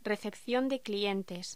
Locución: Recepción de clientes
Sonidos: Voz humana
Sonidos: Hostelería